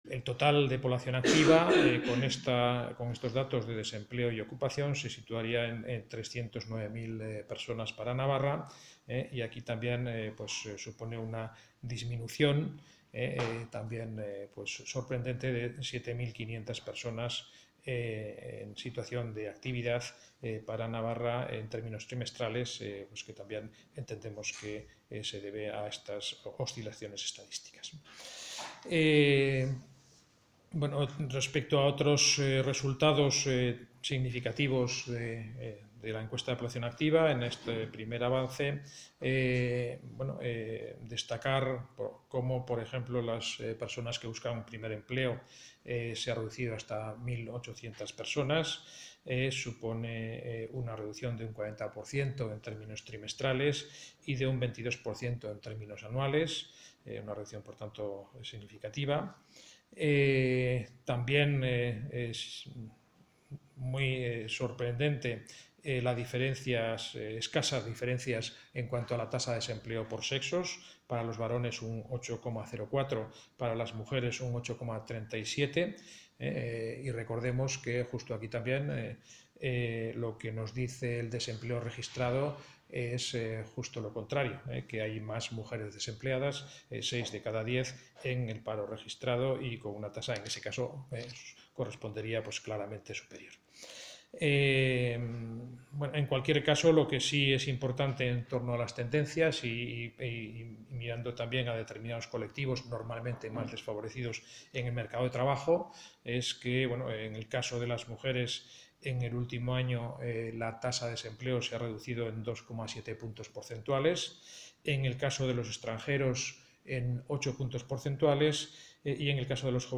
El vicepresidente Laparra presenta los datos de la EPA, que sitúan la tasa de paro en Navarra en el 8,19%